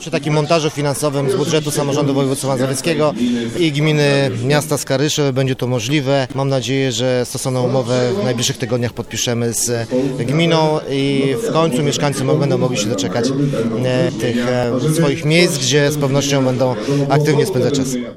O szczegółach mówi Rafał Rajkowski, wicemarszałek województwa mazowieckiego: